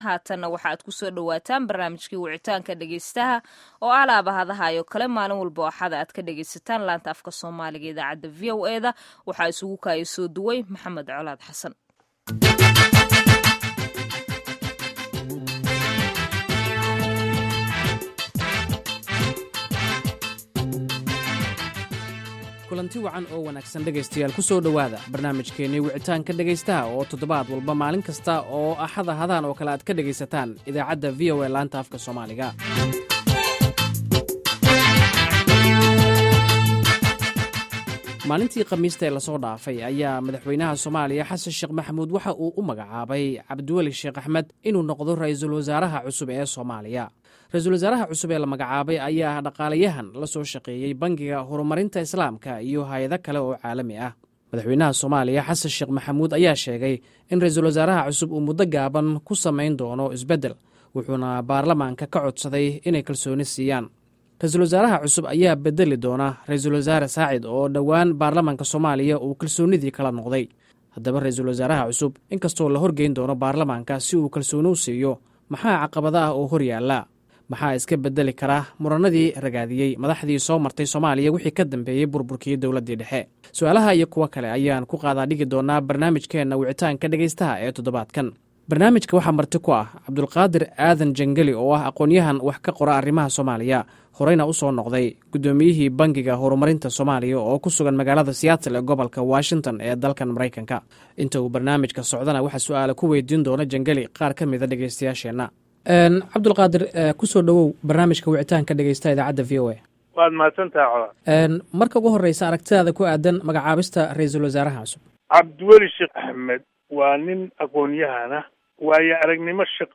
Dhageyso Barnaamijka Wicitaanka Dhageystaha